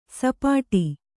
♪ sapāṭi